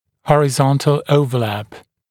[ˌhɔrɪ’zɔntl ˌəuvə’læp][ˌхори’зонтл ˌоувэ’лэп]горизонтальное перекрытие (напр. о резцах; см. также overjet)